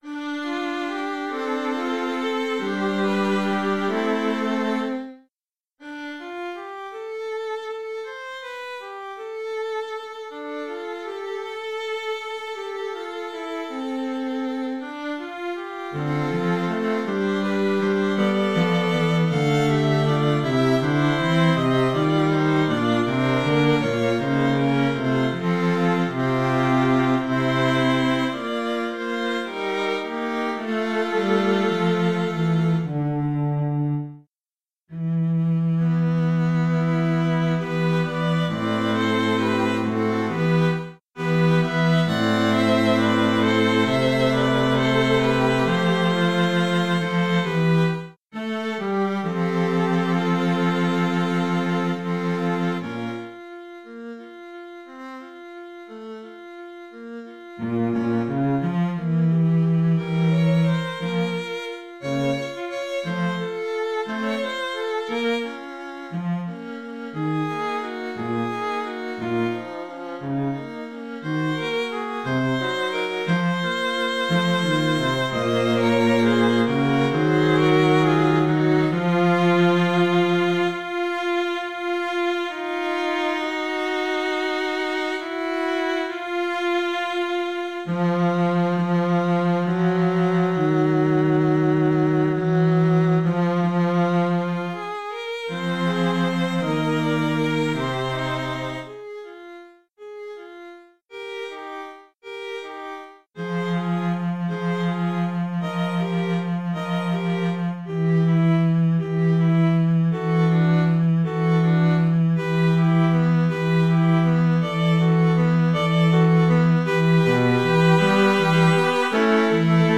SATB
recording (instrumental)